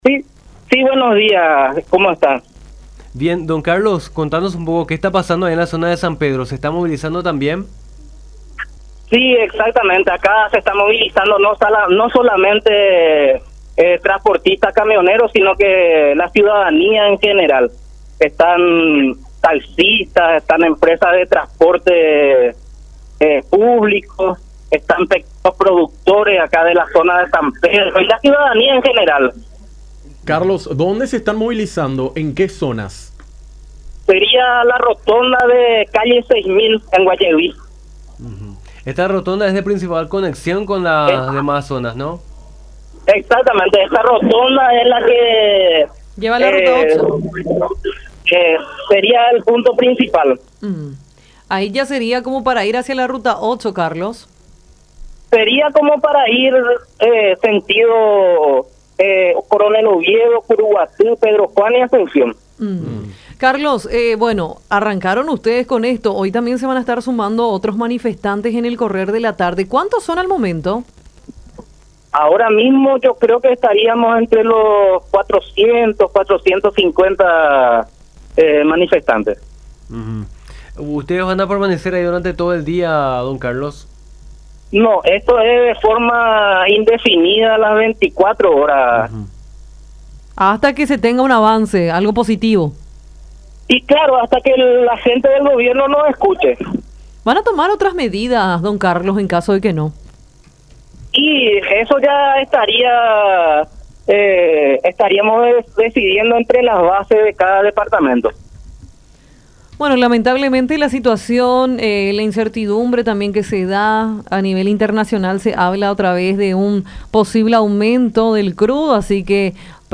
en conversación con Nuestra Mañana por La Unión.